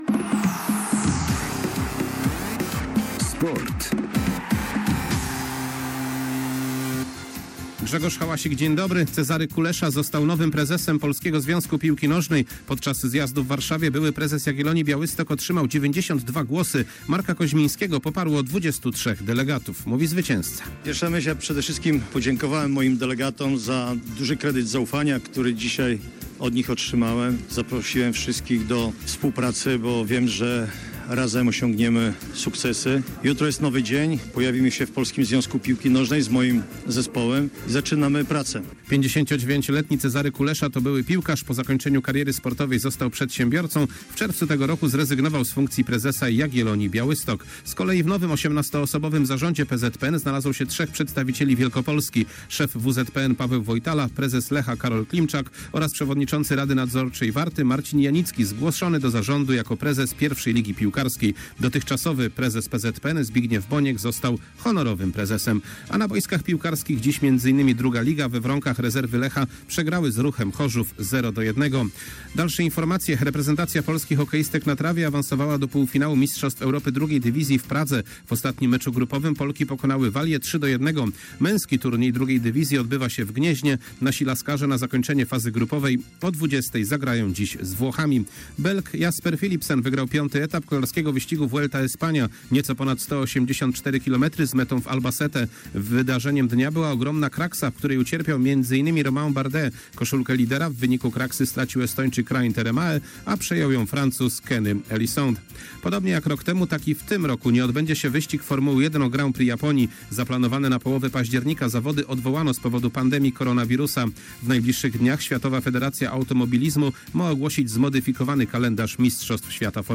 18.08.2021 SERWIS SPORTOWY GODZ. 19:05